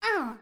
SFX_Mavka_Hit_Voice_03.wav